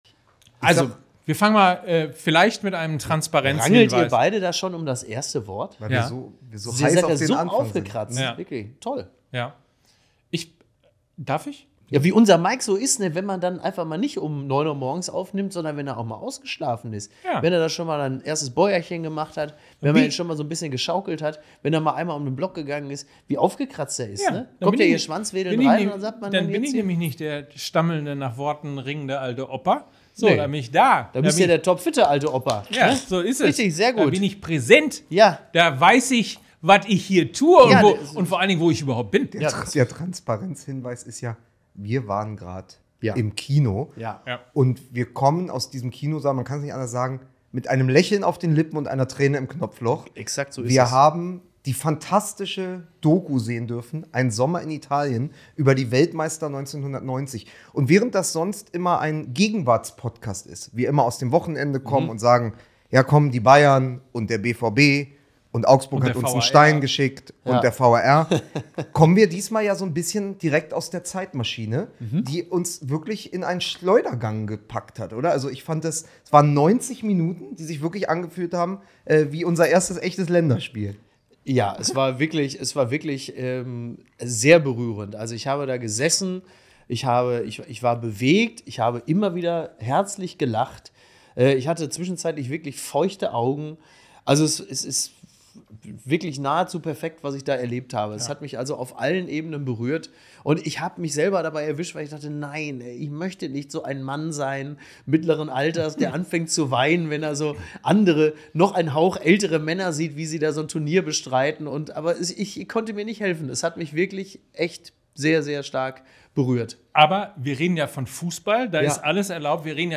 So schwebte er ins Studio, der erste und gleich größte Gast in der Geschichte von FUSSBALL MML.